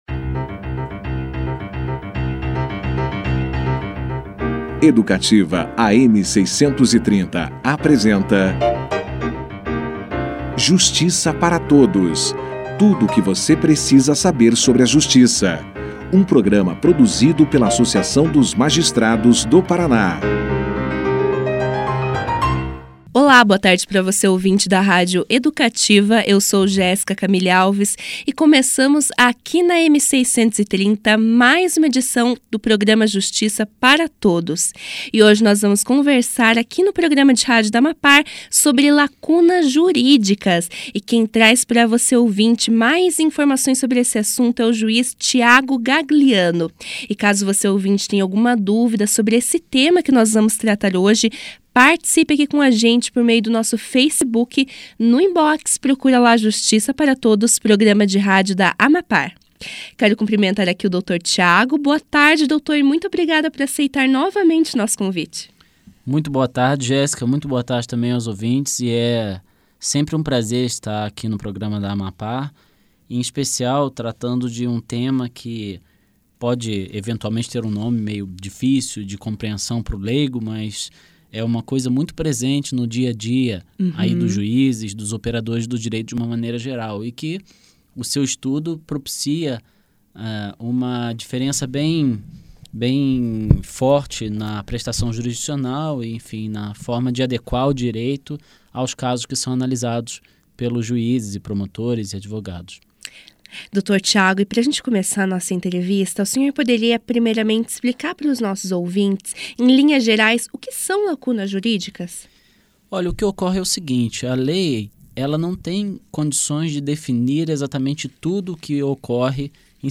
O programa Justiça Para Todos recebeu, nesta quinta-feira (20), o juiz Tiago Gagliano, que explicou aos ouvintes o conceito de lacuna jurídica.
O juiz, que recentemente lançou um livro sobre o tema, deu alguns exemplos comuns de lacunas jurídicas para a melhor compreensão do ouvinte, além de esclarecer a forma de atuação dos magistrados nessas situações. Confira aqui a entrevista na íntegra.